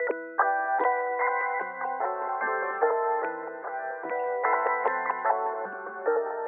椋鸟的声音效果 " 椋鸟短促的声音03
描述：椋鸟的分开的短声音/叽叽喳喳。
声道立体声